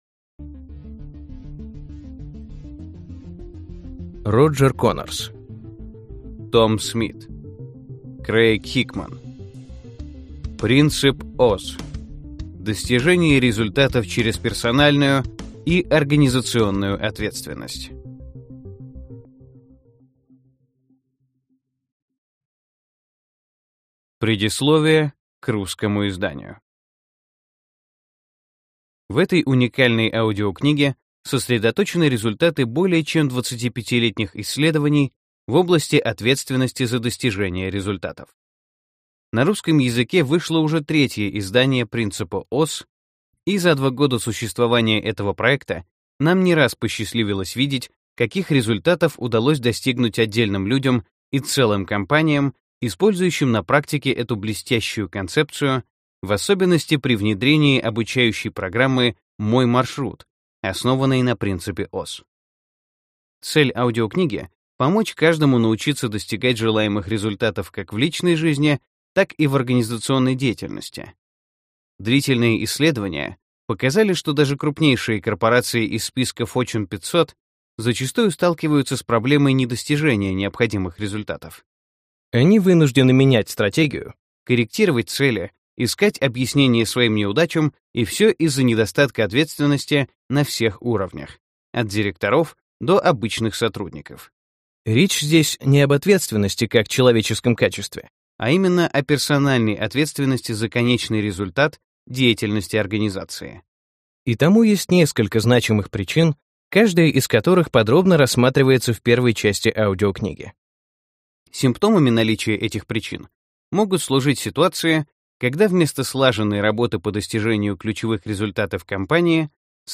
Аудиокнига Принцип Оз. Достижение результатов через персональную и организационную ответственность | Библиотека аудиокниг